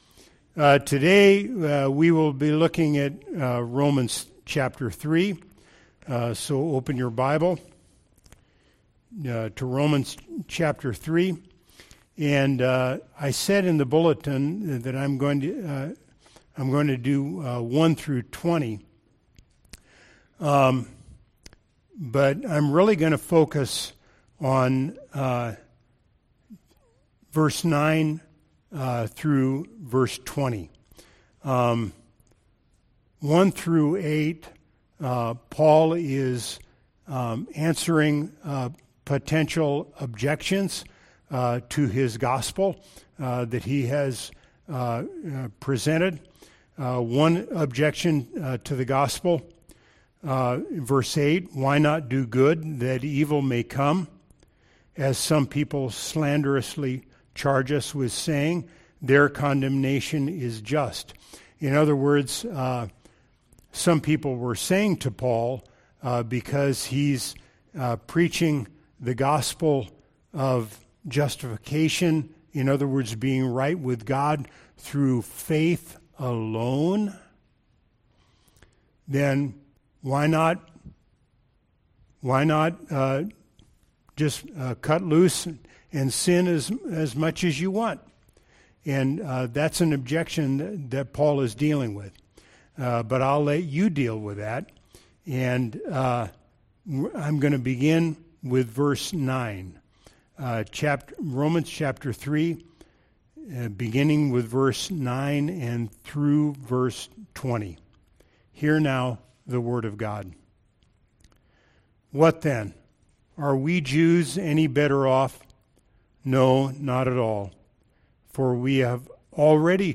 Sermon text: Romans 3:1-20